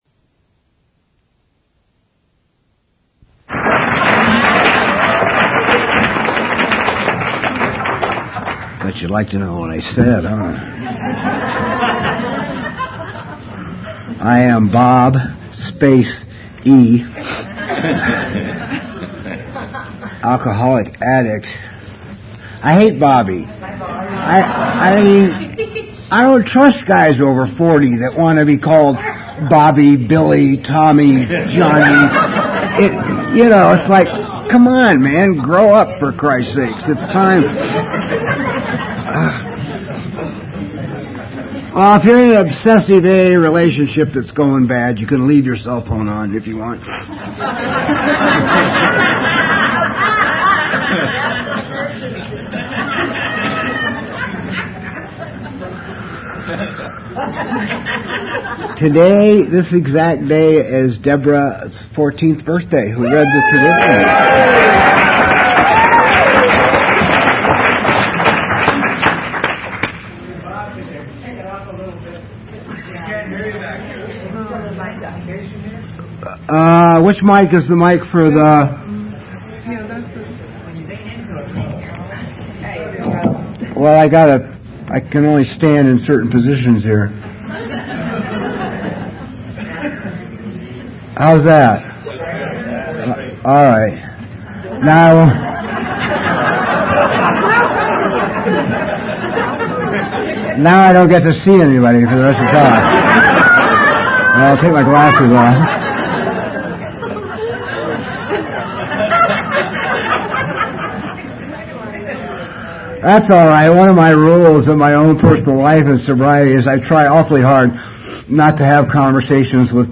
Palm Springs Convention 2001
AA Speaker Audios